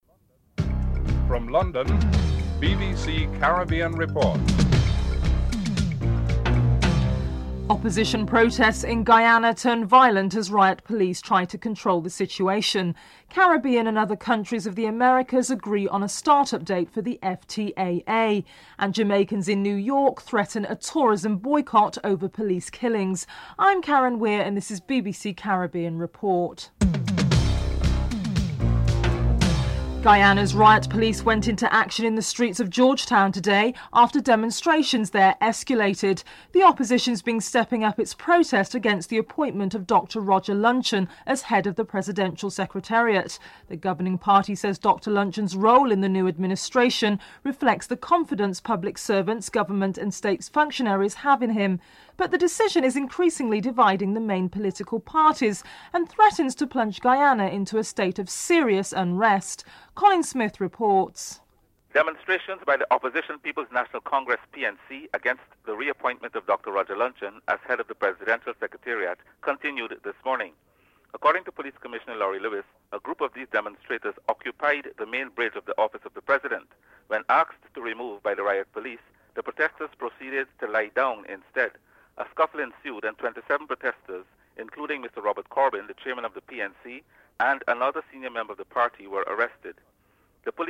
1. Headlines (00:00-00:31)
3. Caribbean and other countries of the Americas agree on a startup date for the Free Trade Area of the Americas (FTAA). Jamaica's Ambassador to Washington Richard Bernal is interviewed (05:22-08:37)